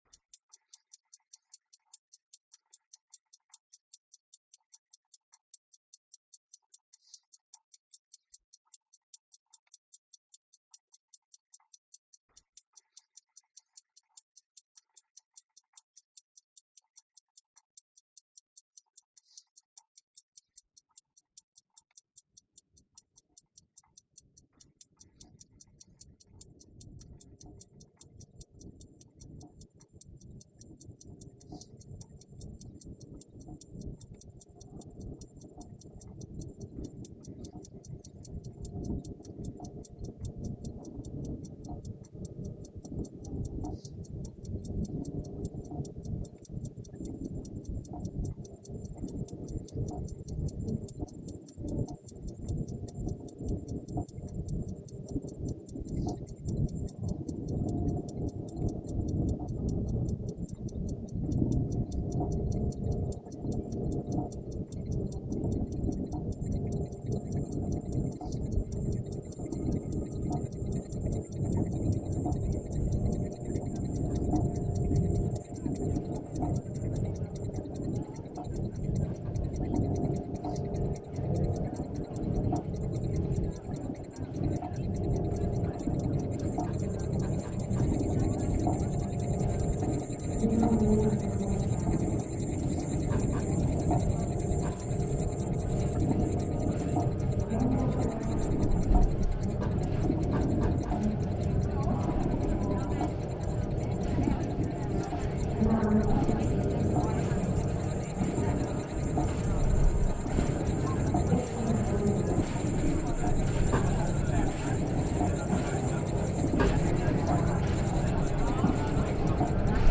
experimental music